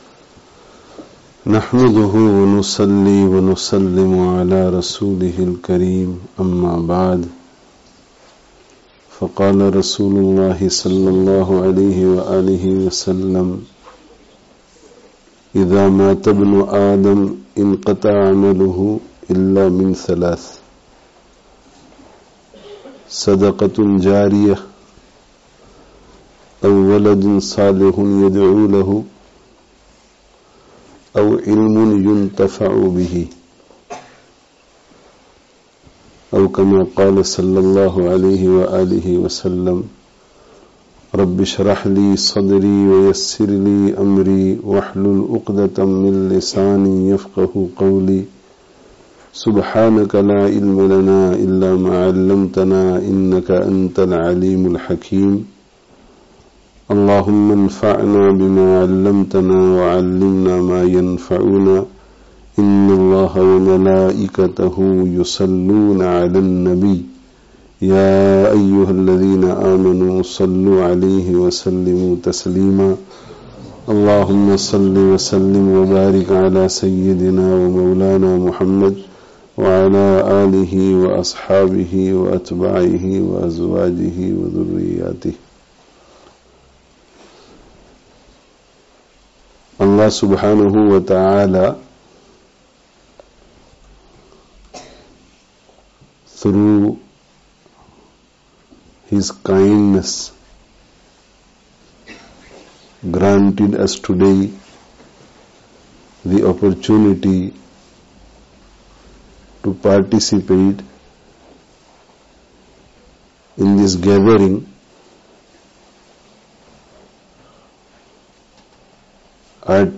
Short Talk [Annual Jalsah] (Al-Hidayah Foundation, Walsall 19/01/19)